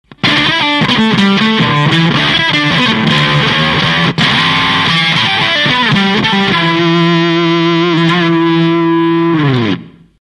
そして「荒馬」にふさわしく全部フル！
「FUZZ EXPLOSION ON２(80kbMP3)」
１２フレットから上を弾くと音程も崩れ
「リングモジュレータ的なサウンド？が・・・